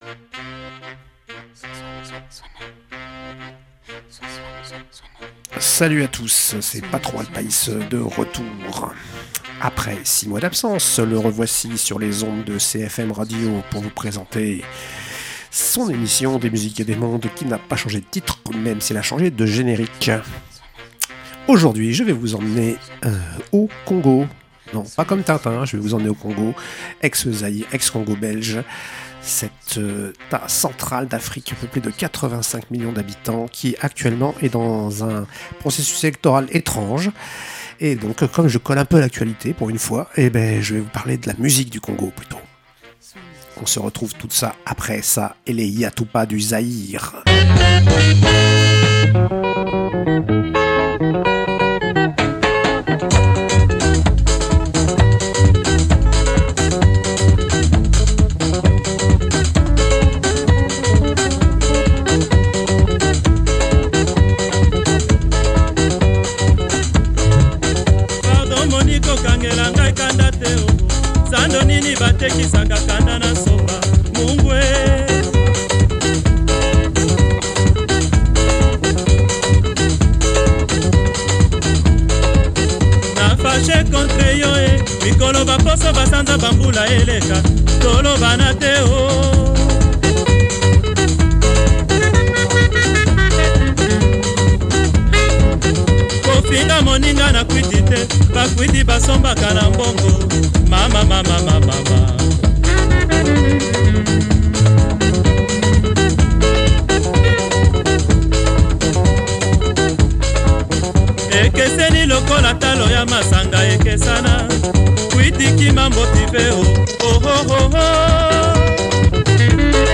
Un petit tour d’horizon des musiques de la RDC, de la rumba au soukous à travers plusieurs époques.